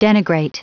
Prononciation du mot denigrate en anglais (fichier audio)
Prononciation du mot : denigrate